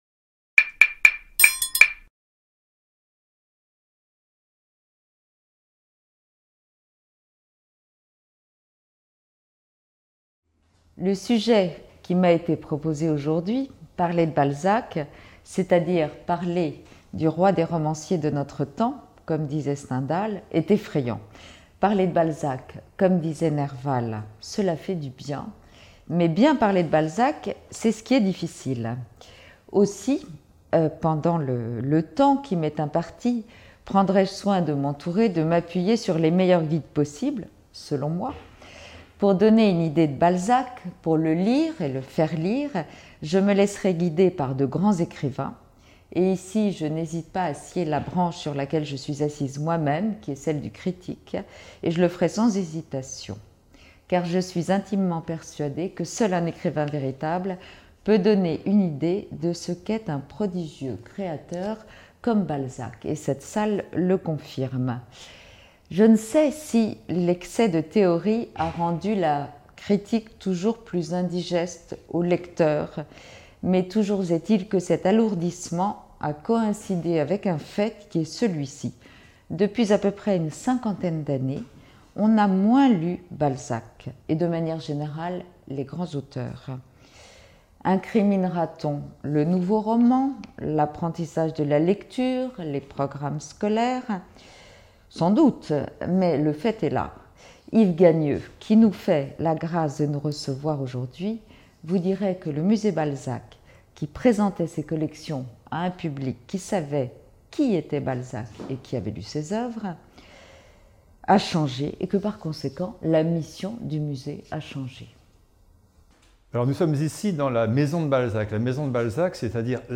« Aimer Balzac » aurait aussi pu être le titre de cette vidéo, tournée à la Maison de Balzac (Paris 16e)